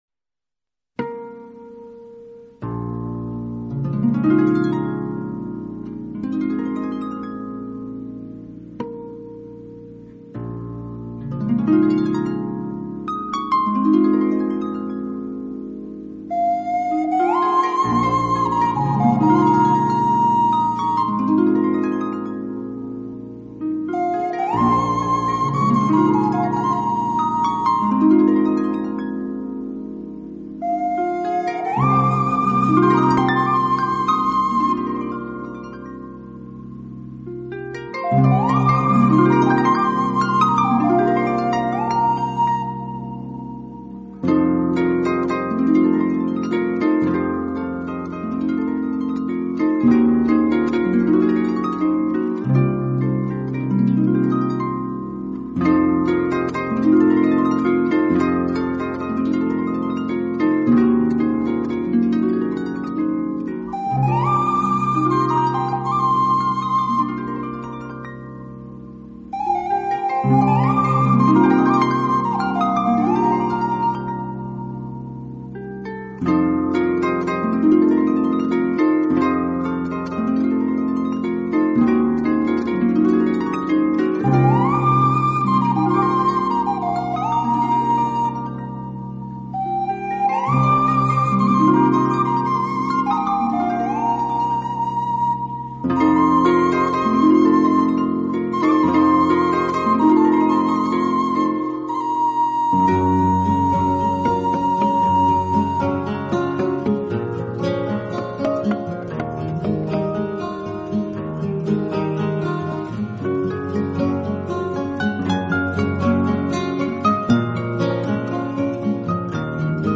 竖琴专辑
带给我们的欢乐，他的作品悠闲富有情趣，没有那股纵欲过度、自我毁灭的倾向。